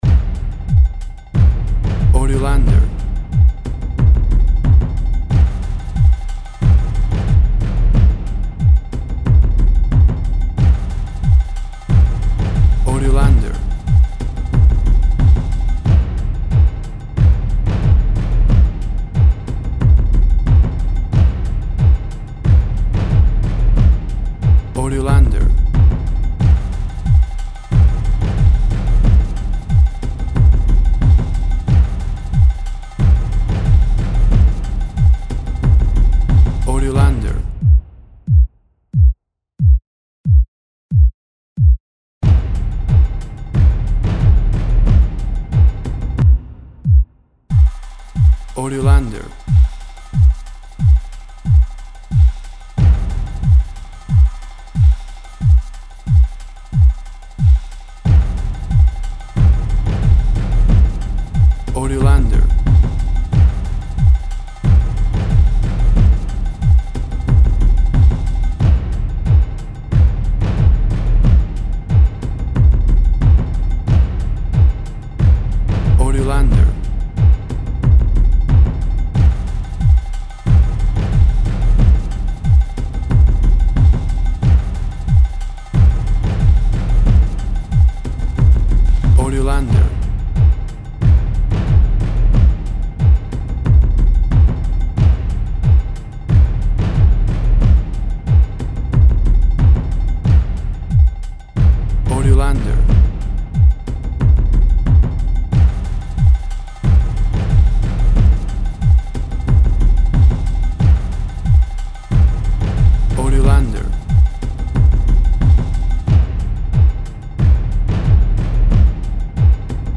WAV Sample Rate 16-Bit Stereo, 44.1 kHz
Tempo (BPM) 95